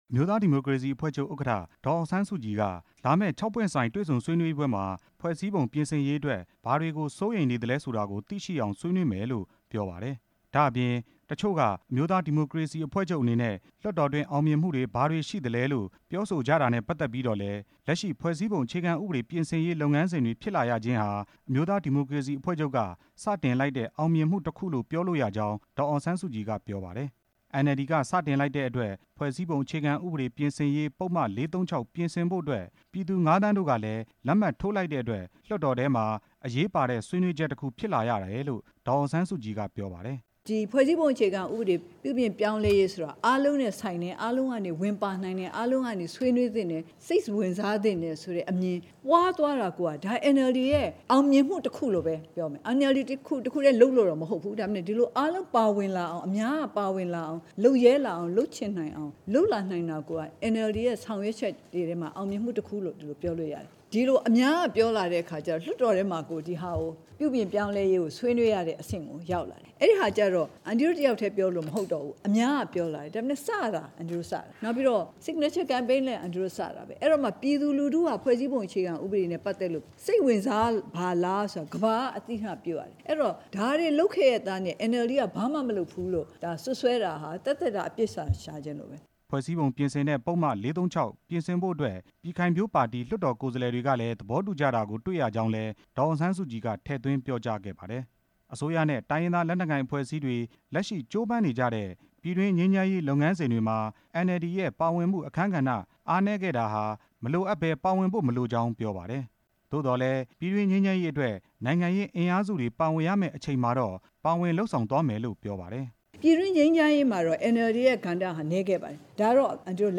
ဒေါ်အောင်ဆန်းစုကြည်ရဲ့ သတင်းစာရှင်းလင်းပွဲ